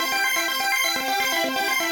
Index of /musicradar/shimmer-and-sparkle-samples/125bpm
SaS_Arp02_125-C.wav